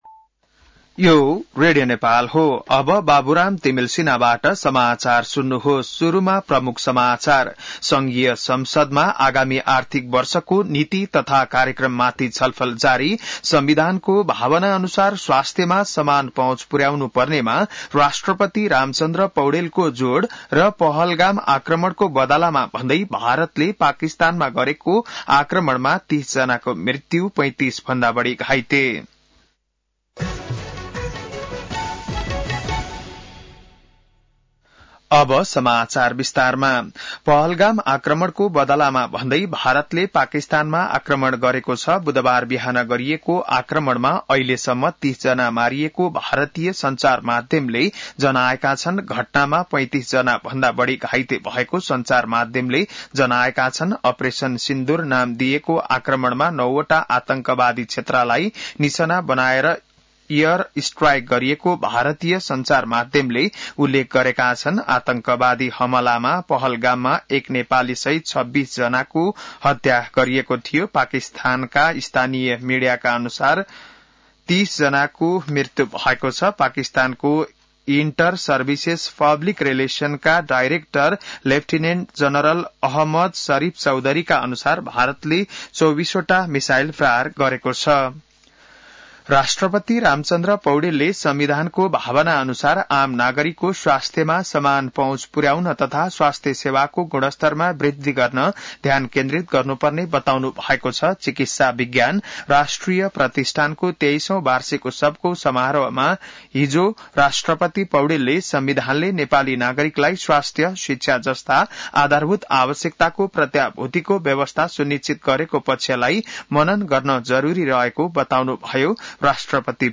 बिहान ९ बजेको नेपाली समाचार : २४ वैशाख , २०८२